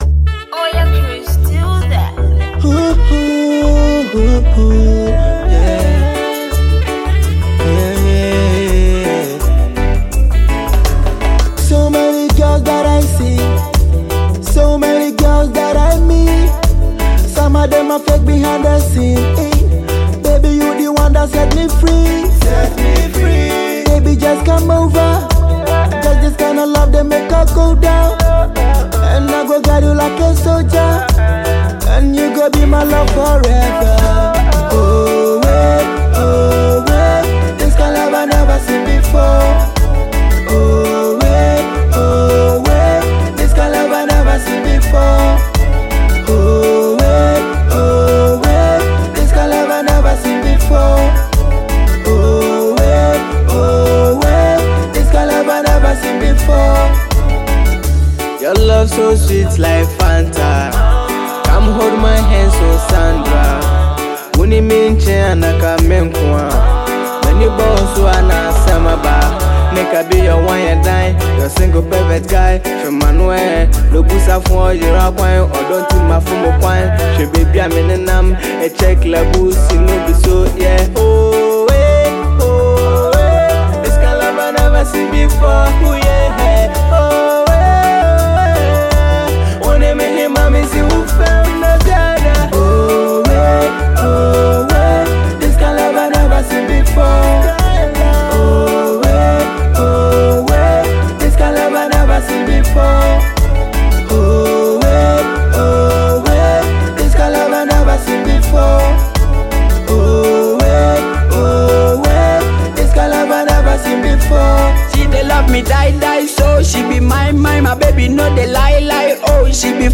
love tune